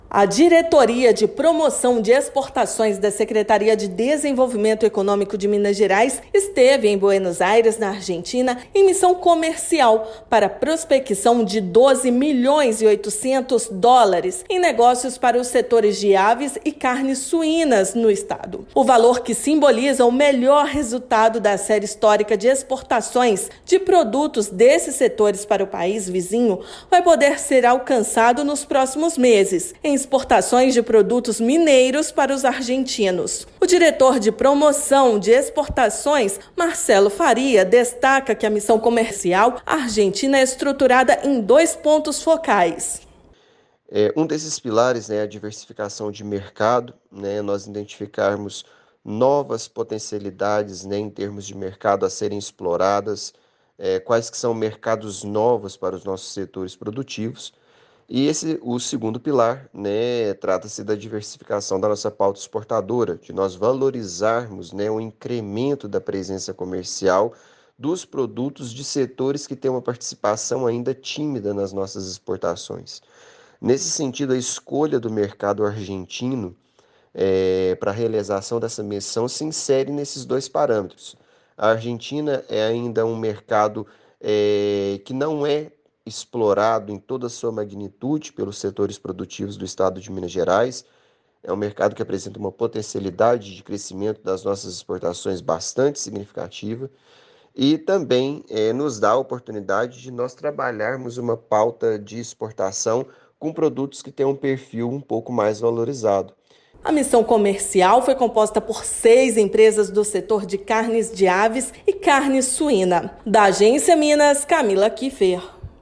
Em Buenos Aires, Governo de Minas projeta avanços em mercados de aves e carnes suínas. Ouça matéria de rádio.